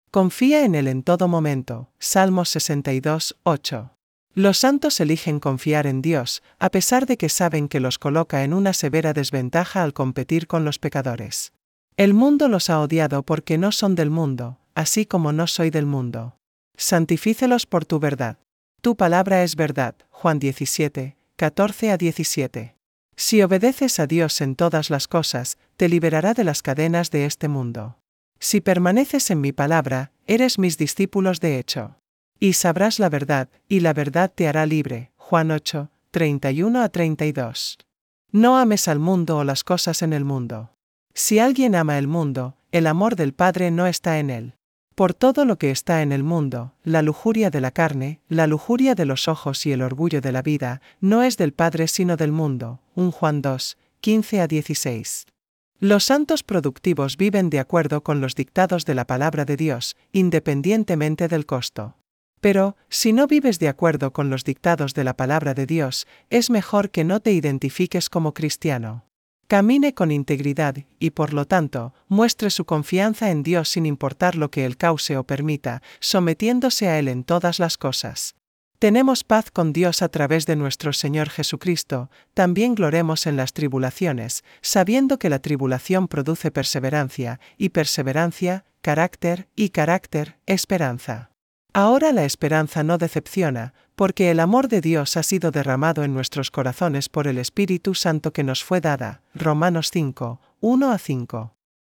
September 1 Evening Devotion